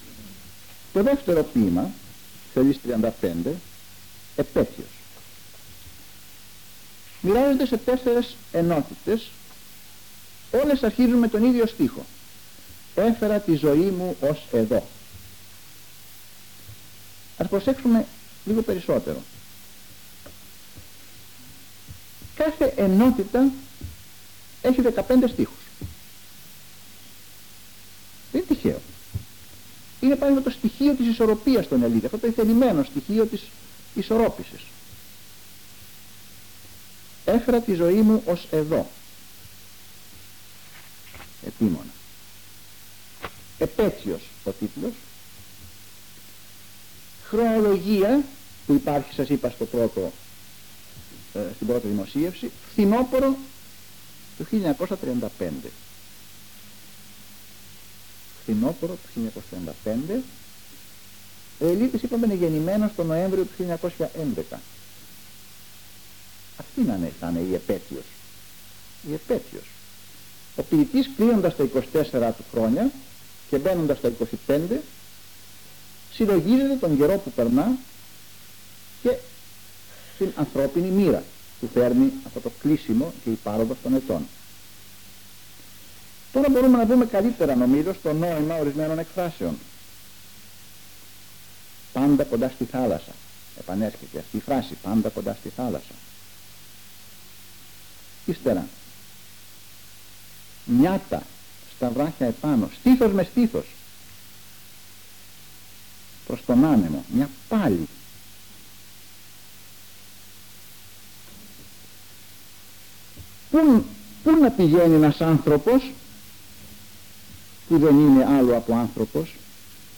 Εξειδίκευση τύπου : Εκδήλωση
Περιγραφή: Κύκλος Μαθημάτων με γενικό Θέμα "Σύγχρονοι Νεοέλληνες Ποιητές"